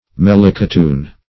melicotoon - definition of melicotoon - synonyms, pronunciation, spelling from Free Dictionary Search Result for " melicotoon" : The Collaborative International Dictionary of English v.0.48: Melicotoon \Mel`i*co*toon"\, n. (Bot.)
melicotoon.mp3